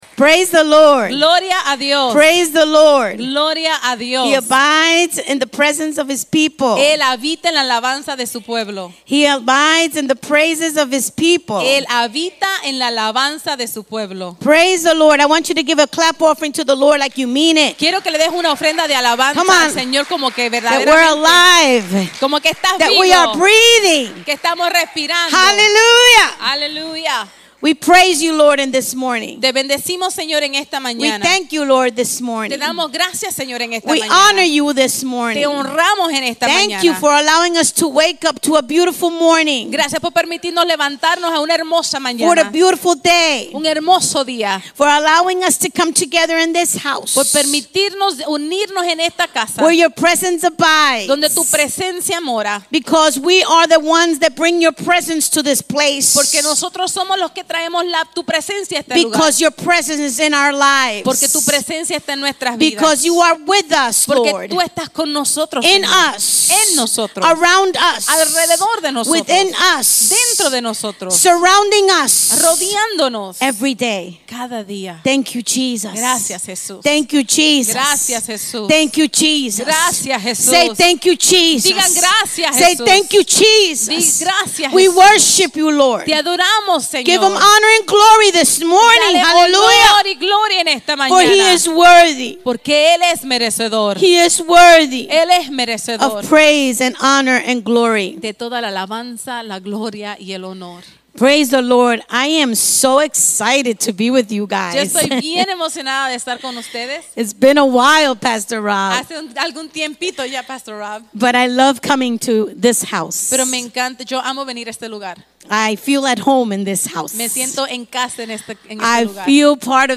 Sermons | New Walk Church